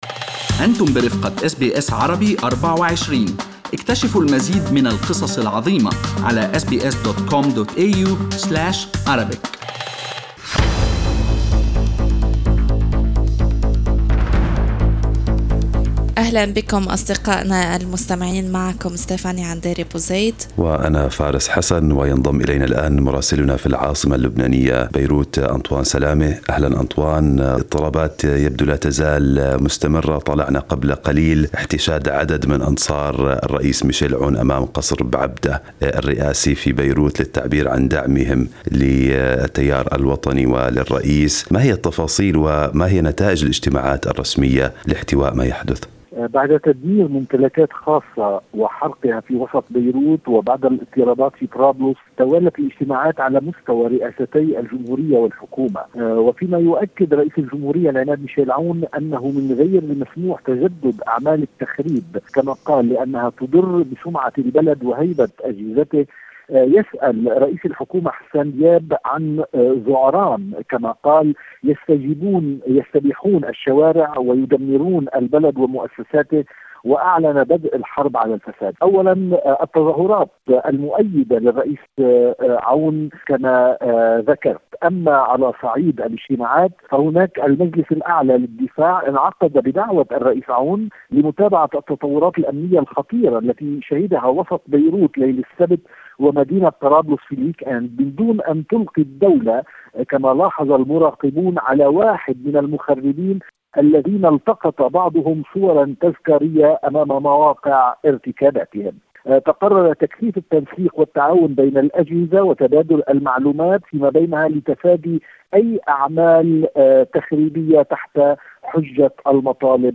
من مراسلينا: أخبار لبنان في أسبوع 16/06/2020